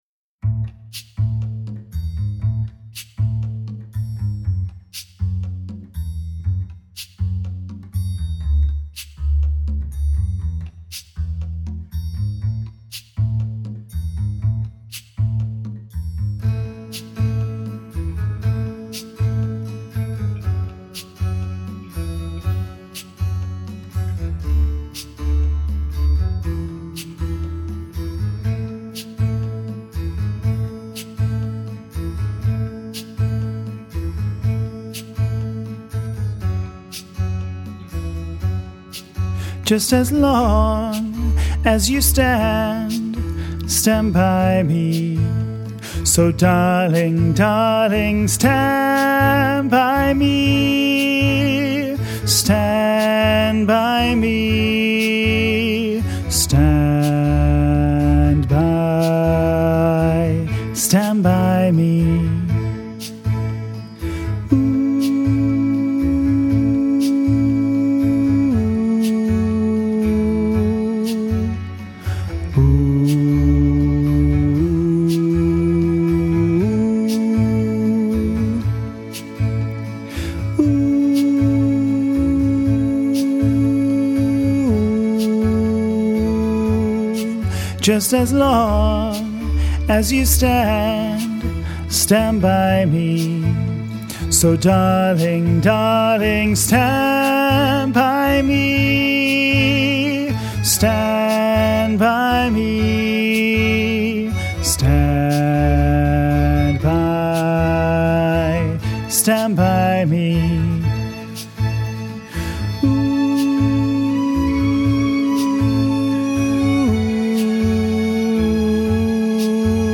stand-by-me-bass.mp3